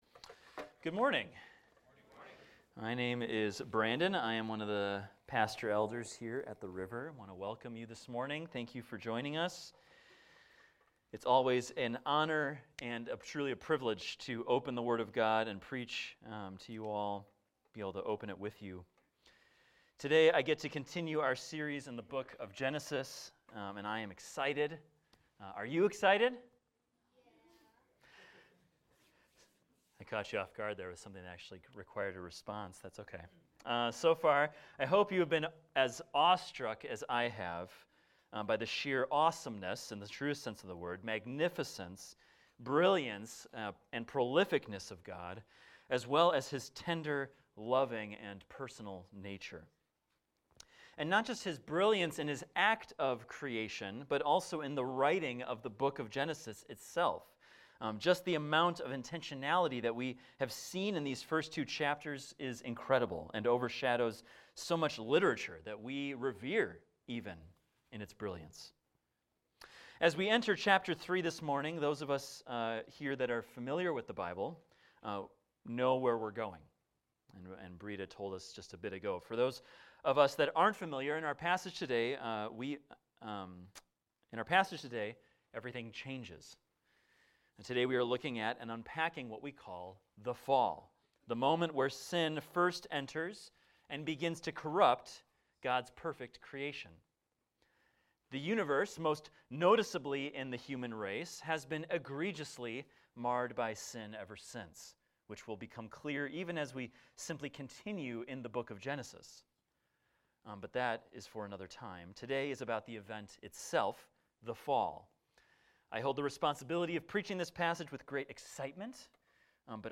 This is a recording of a sermon titled, "The Fall."